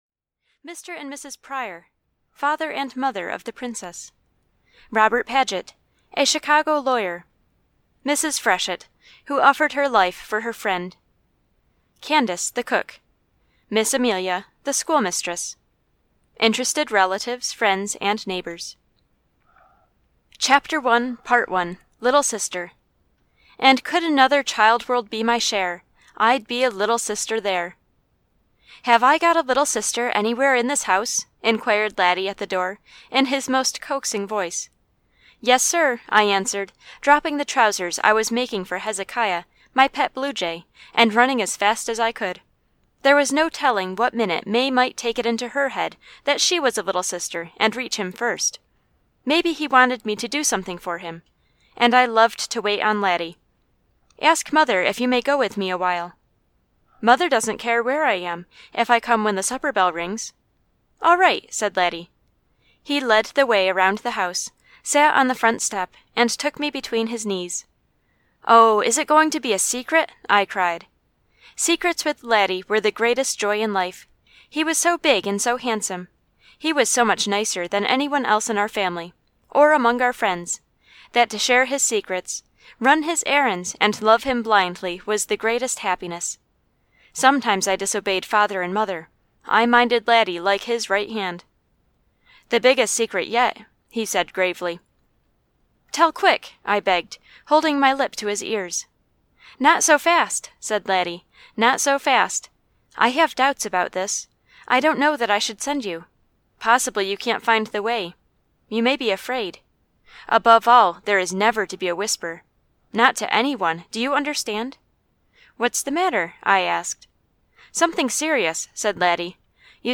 Audio knihaLaddie (EN)
Ukázka z knihy